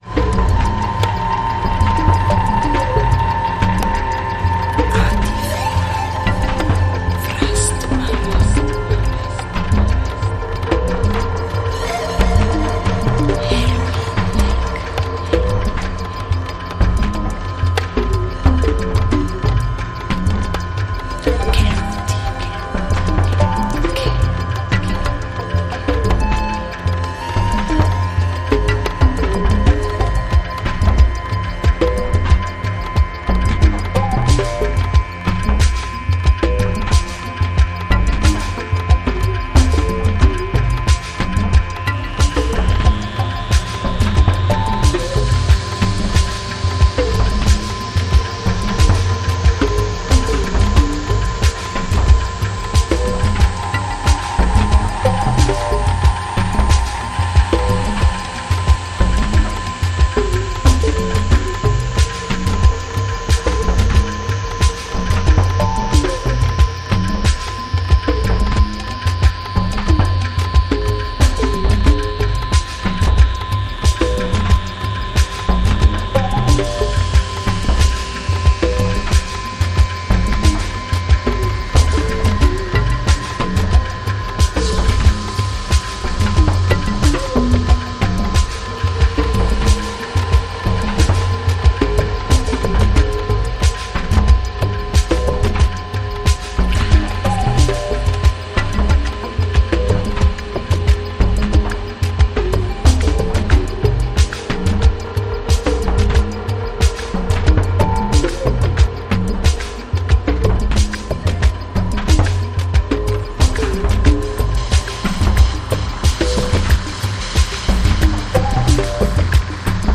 ライナーノーツ封入、音質も問題なさそうです。